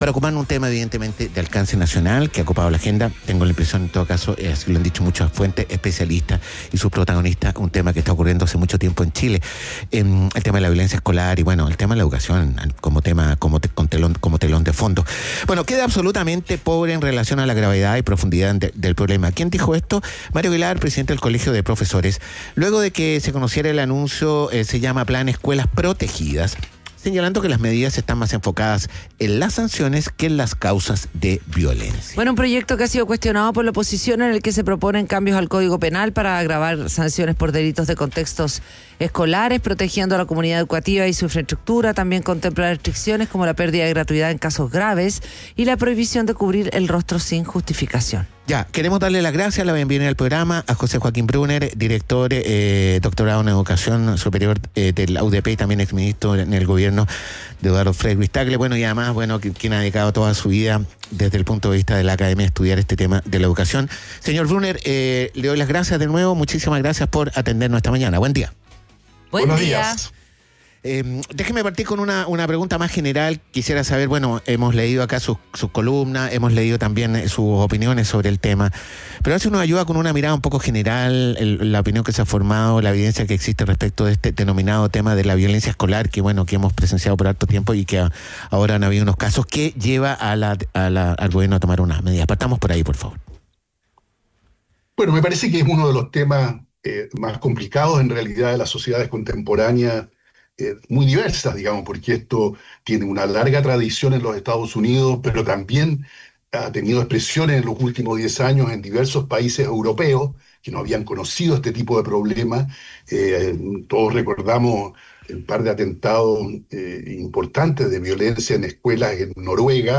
Entrevista: violencias en la educación - José Joaquín Brunner